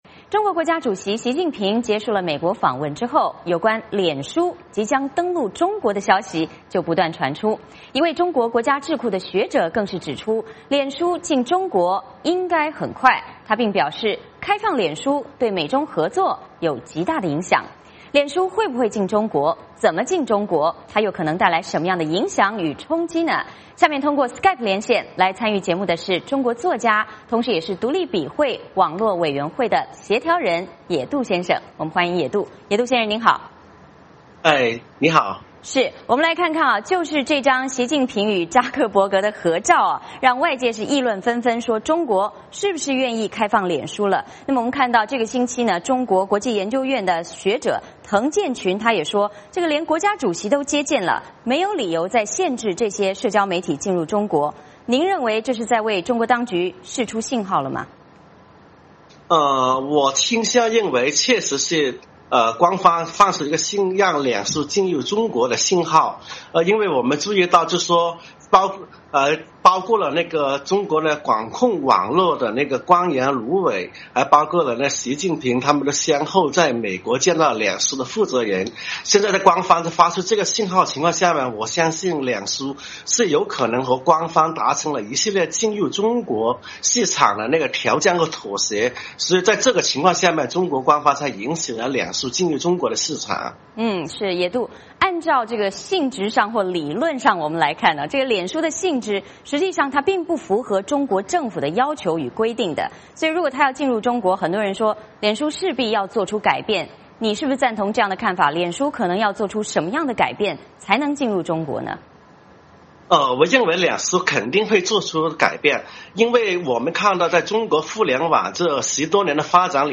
脸书会不会进中国?怎么进?又可能带来什么样的影响与冲击?下面通过SKYPE连线参与节目的是中国作家